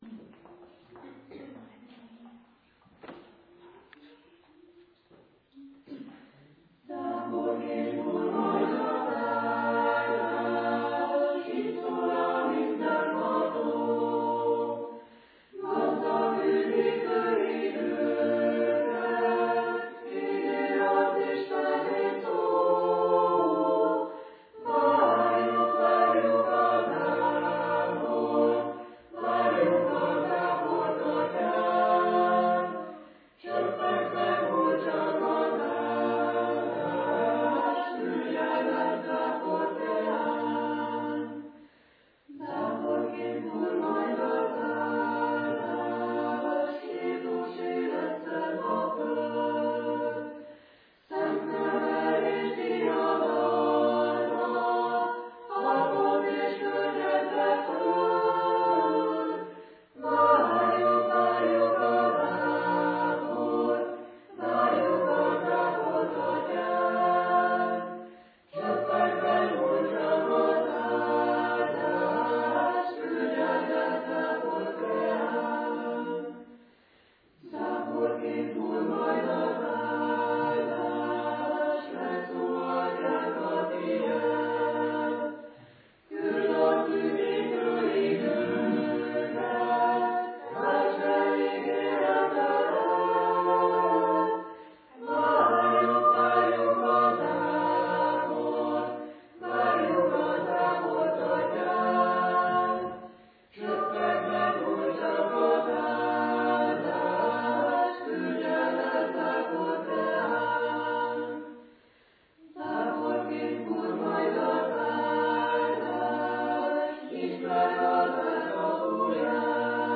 igehirdetése